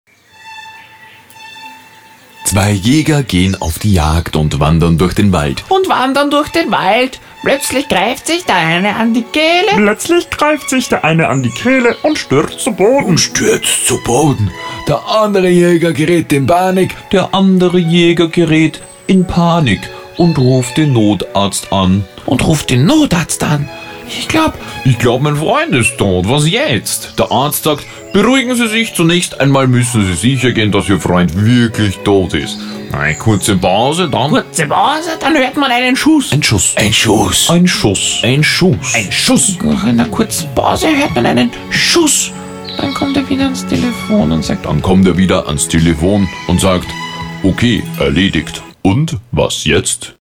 30+, unverwechselbar, diverse Comic-Stimmen, von jugendlich schrill über sportlich hektisch bis kompetent seriös
Sprechprobe: Sonstiges (Muttersprache):